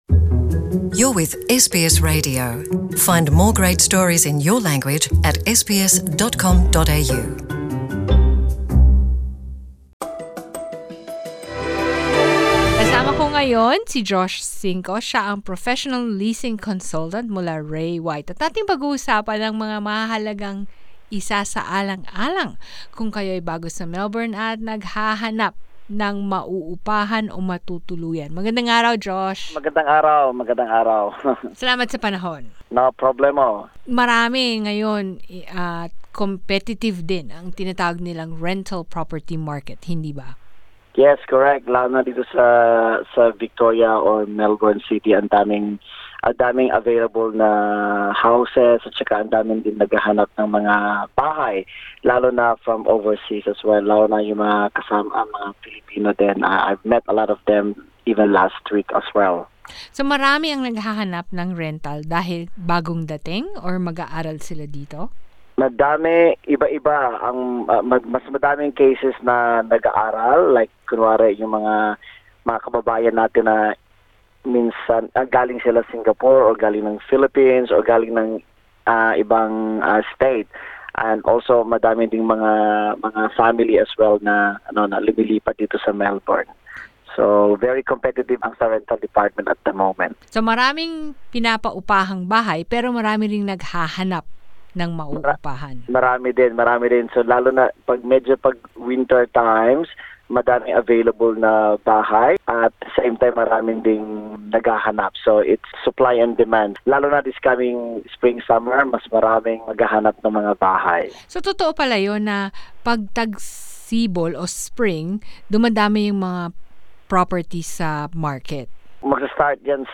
In this podcast we speak to a leasing consultant to give us answers on what you can do to get a rental lease if you’ve never had one before.